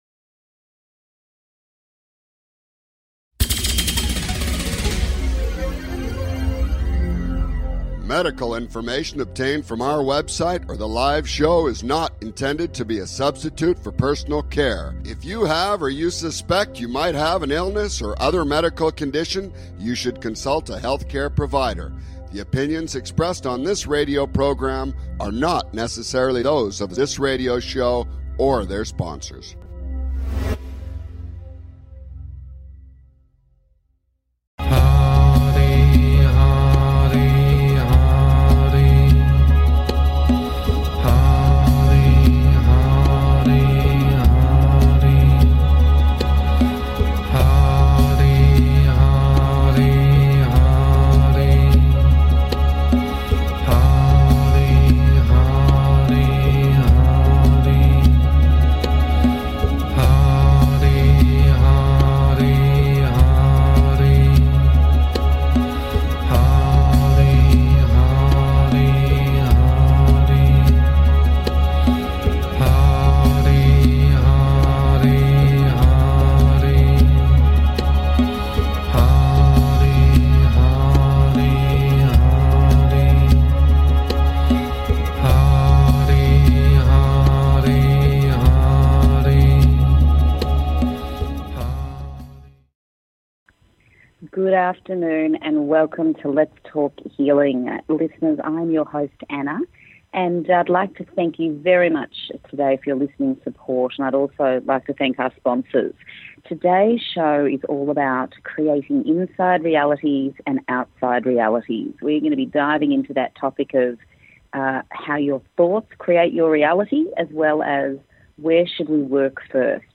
Talk Show Episode, Audio Podcast, Lets Talk Healing and Creating our outside realities and inside realities on , show guests , about outside realities,inside realities,create our realities,where to start first,energetic intention,action steps,creating your reality,focus on feeling,perseverance,Sacred Inner Dialogue, categorized as Health & Lifestyle,Alternative Health,Energy Healing,Kids & Family,Philosophy,Motivational,Spiritual,Access Consciousness,Psychic & Intuitive